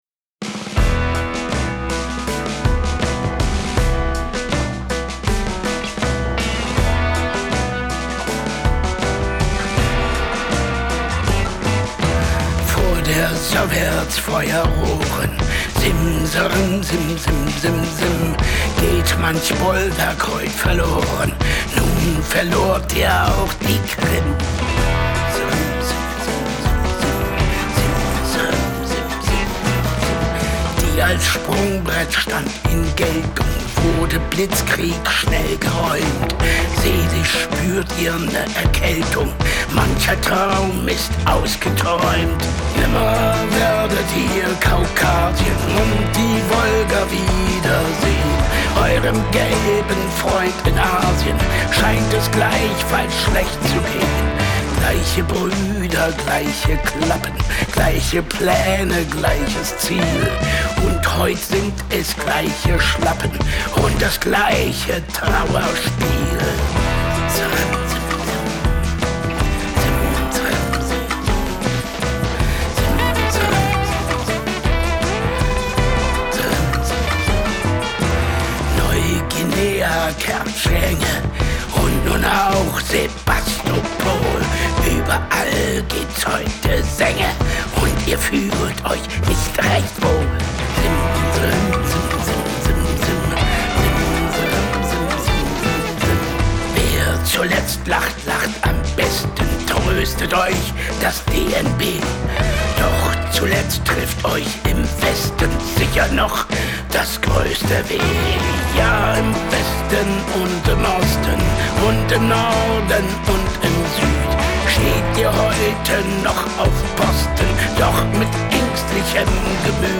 gitaar, zang
piano, gitaar, zang
gitaar, bas, zang
drums, percussie